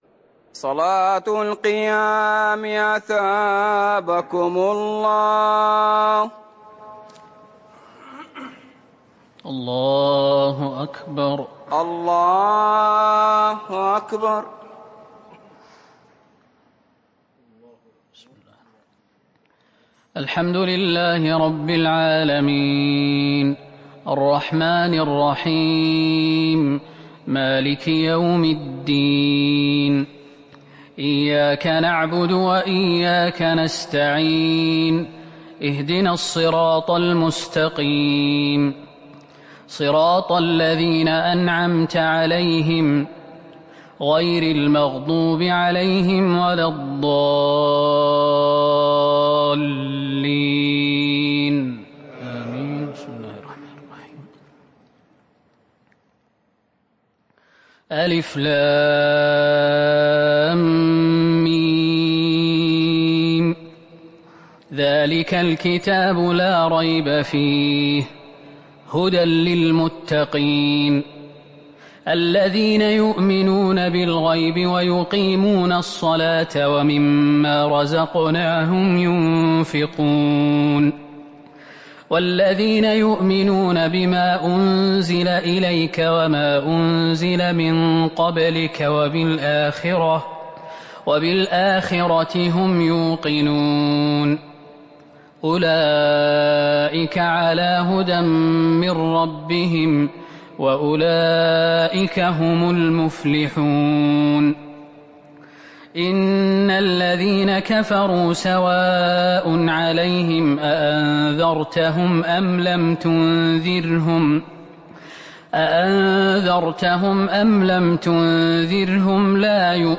تراويح ٣٠ رمضان ١٤٤١هـ من سورة البقرة ١-٦٦ > تراويح الحرم النبوي عام 1441 🕌 > التراويح - تلاوات الحرمين